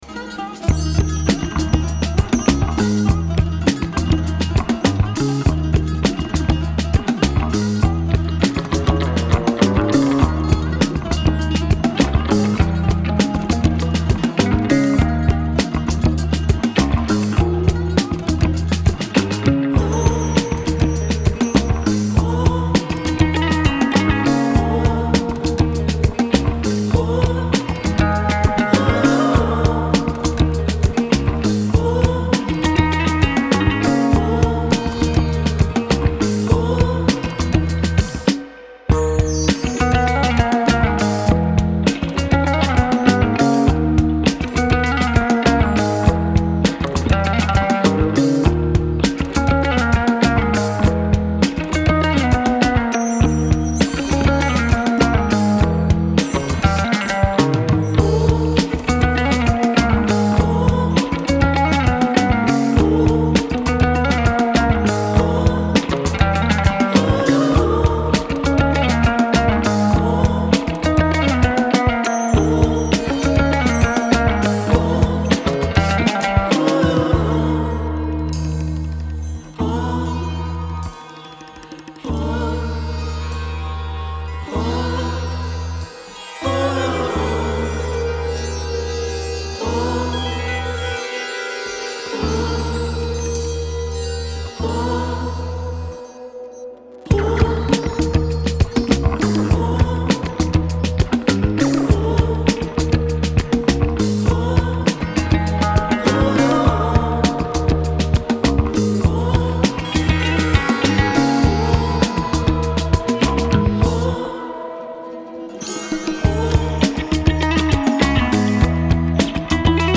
Alternative Rock Instrumental